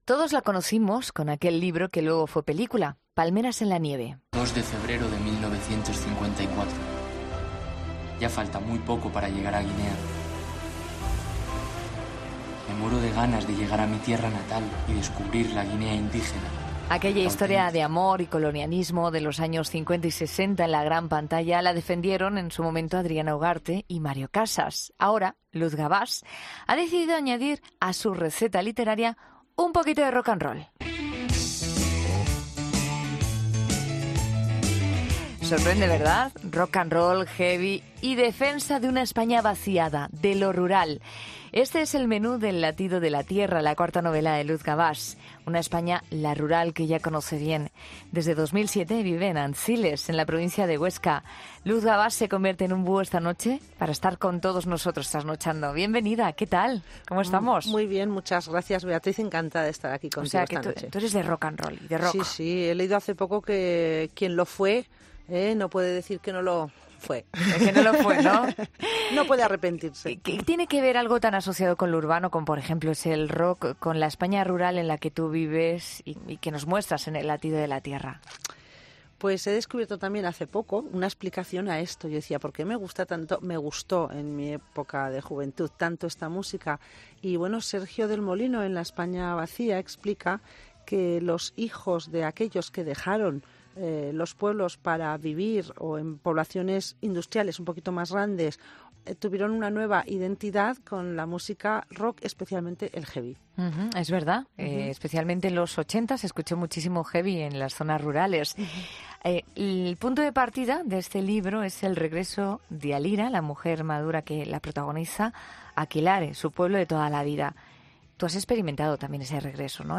AUDIO: La escritora ha estado en 'La Noche' hablando sobre 'El latido de la tierra'